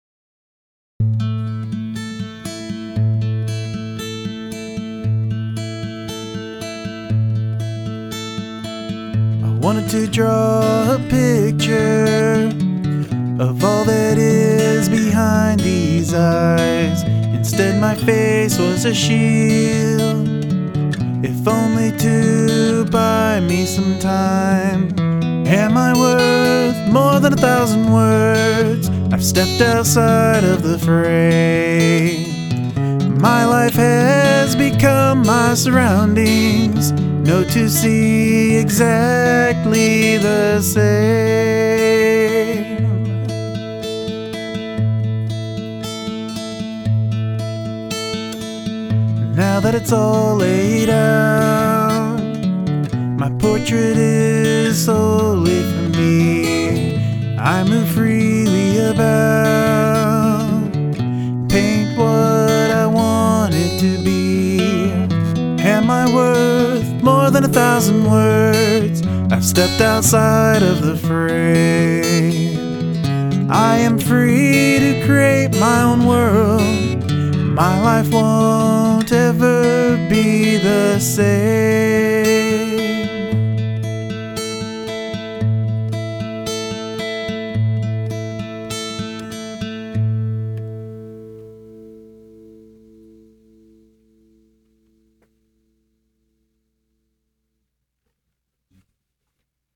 Love that full guitar picking sound and the vocals are vulnerable and sound just right.
It's really short, but sometimes short songs are the best because they get to the point and leave you wanting more.
The guitar sounds really good - both the playing and the recording.  Vocals are performed well, but might blend better if you backed them down just a little and added a slight bit of delay or more reverb.
So beautiful and very clear production.
I love your guitar playing here, it is really delicate and suits the lyrics very well.